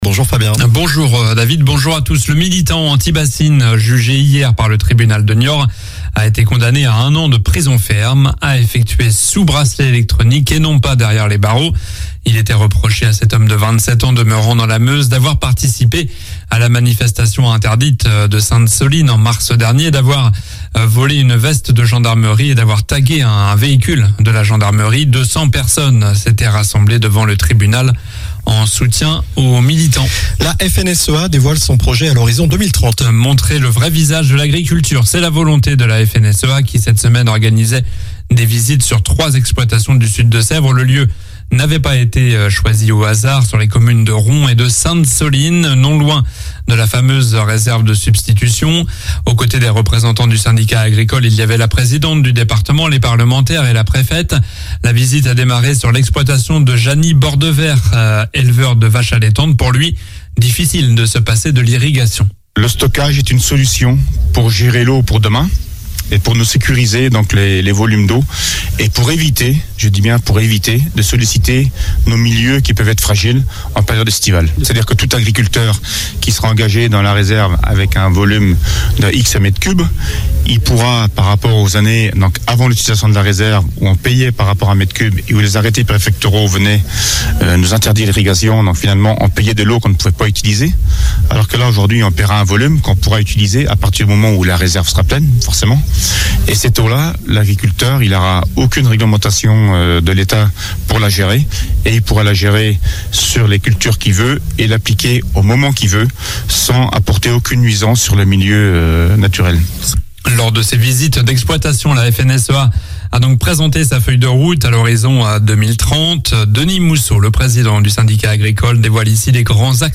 Journal du vendredi 28 juillet (matin)